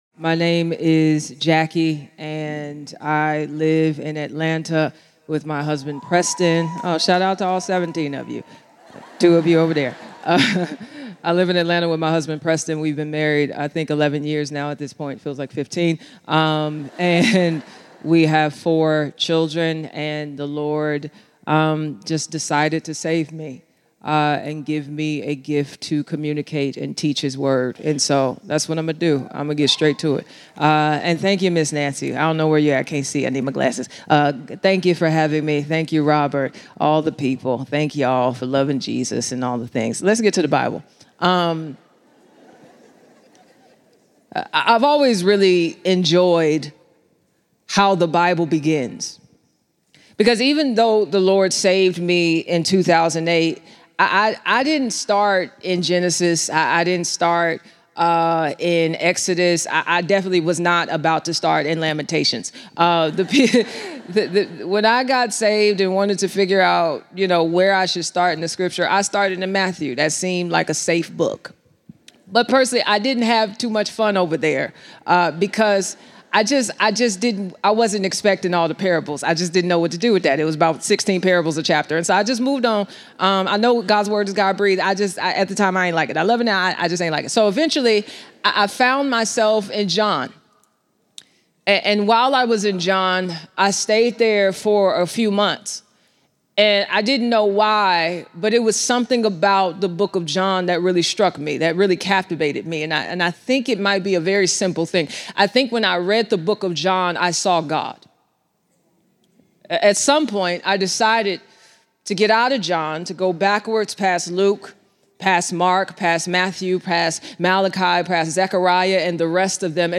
That’s the question Jackie Hill Perry addresses in her message from True Woman '25.